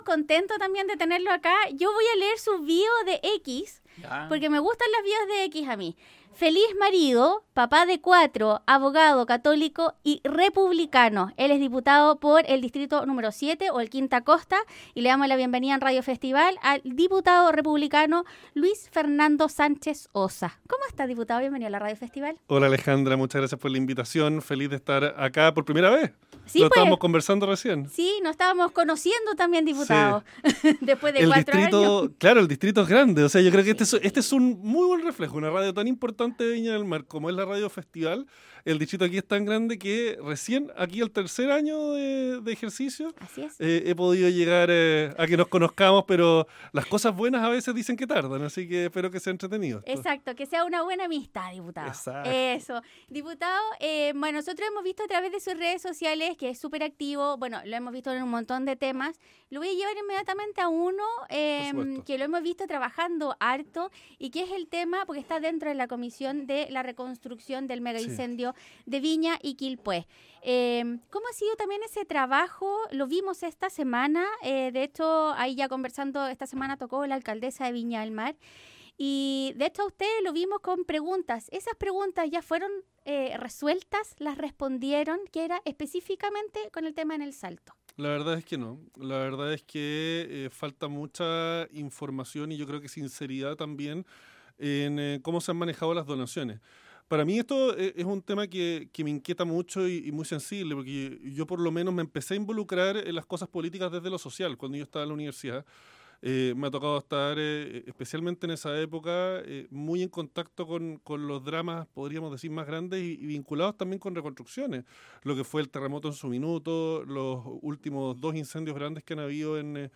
El Diputado Republicano del Distrito 7 estuvo en los estudios de Radio Festival para analizar temas como la comisión investigadora de la reconstrucción del Mega Incendio, crisis financiera de las Fuerzas Armadas y otros temas.